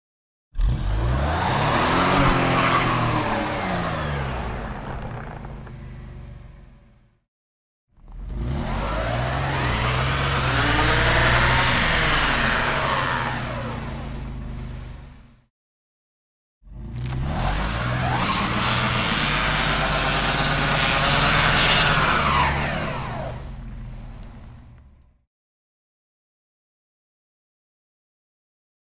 Звуки машины, снега
Машина буксует с переменным звуком то быстро то медленно